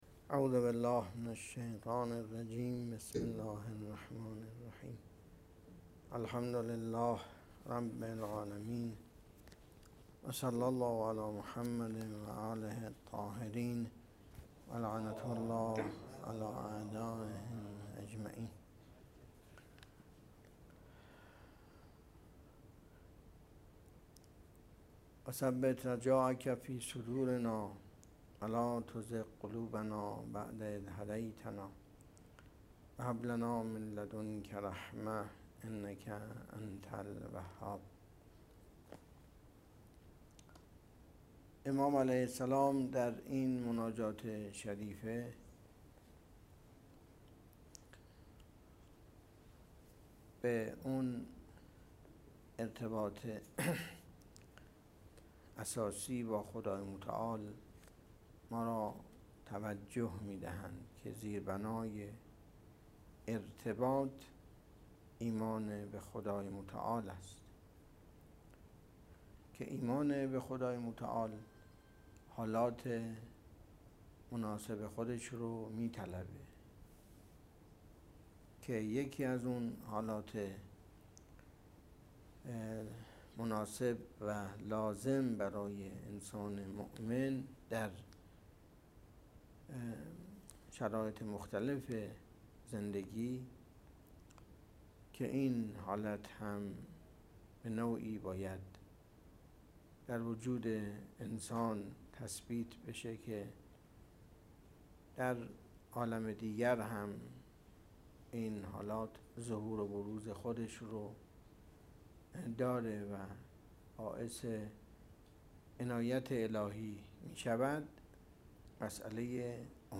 شرح مناجات#ابوحمزه_ثمالی) 🗒شب بیست و هفتم ماه مبارک رمضان ۱۴۰۱
درس اخلاق
سخنرانی-27-رمضان.mp3